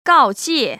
告诫[gàojiè] 훈계하다, 경고를 주다, 경고하다